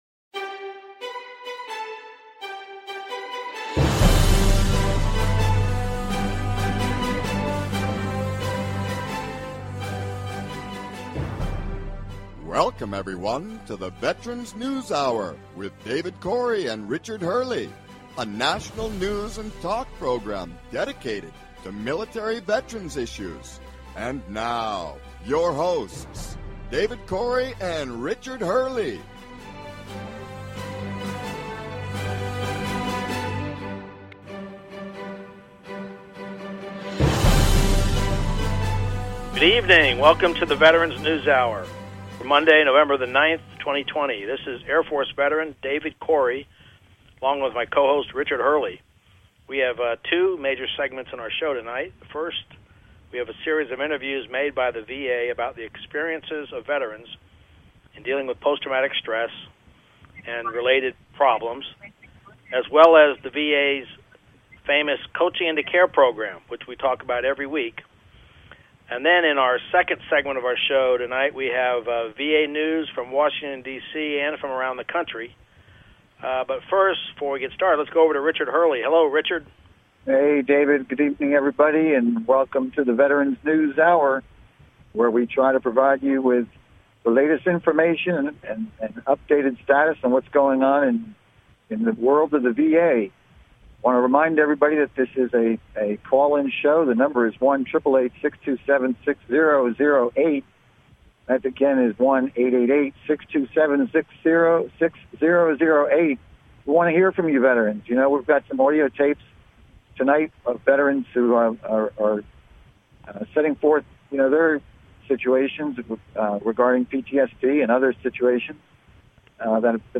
News and talk show about military veterans issues, including VA benefits and all related topics.